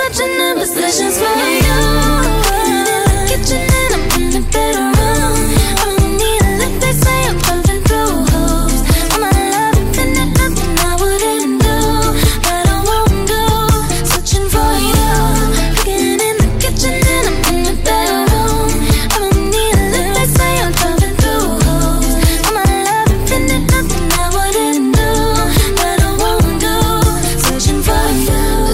Thể loại nhạc chuông: Nhạc âu mỹ